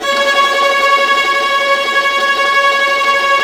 Index of /90_sSampleCDs/Roland - String Master Series/STR_Vlas Bow FX/STR_Vas Tremolo